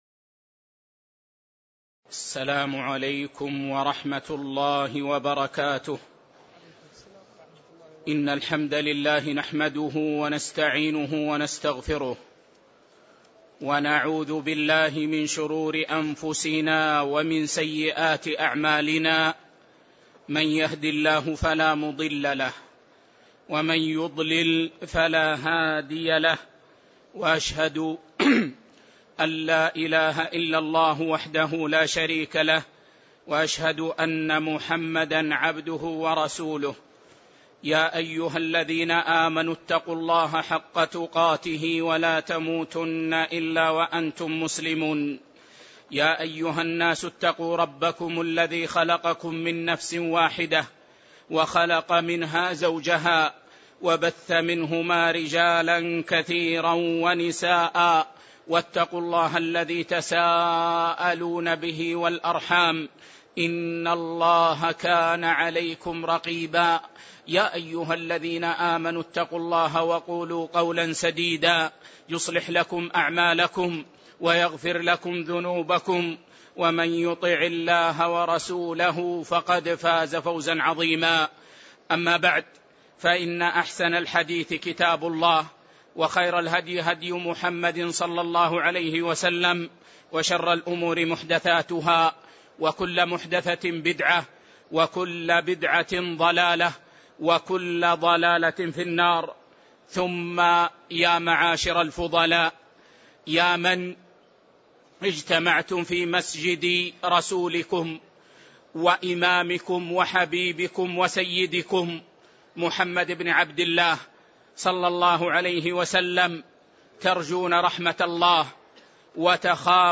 تاريخ النشر ١٨ جمادى الأولى ١٤٣٧ هـ المكان: المسجد النبوي الشيخ